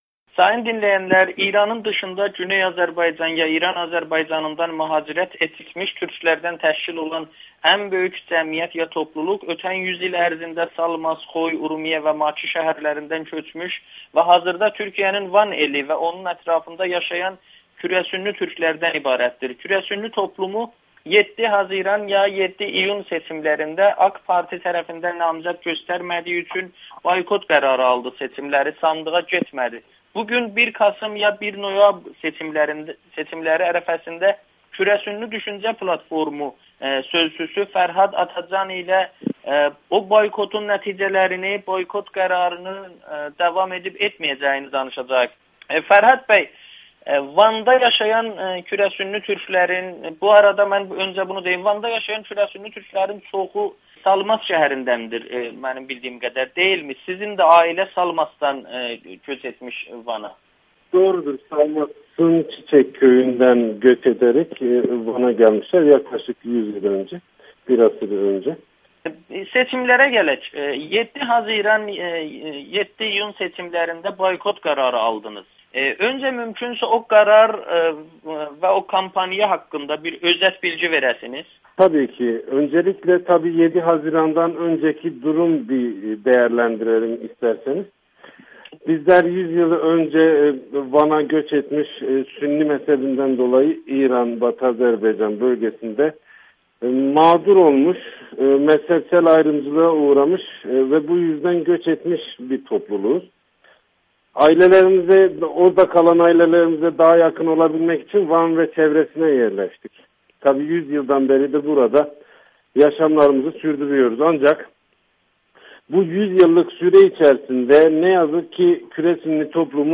Türkiyədə seçkilər və Vanda yaşayan Kürəsünni Türkləri [Audio-Müsahibə]